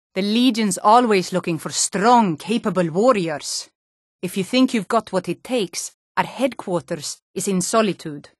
what i’ve been trying to get to sound the same is pretty professionally recorded audio (Skyrim’s dialogue), tho sadly it’s been lossy compressed, bitrate wise.
IMO yes, the attack on the plosives such as “K” “P” “T” is too pronounced : too clicky, a bit Khoisan.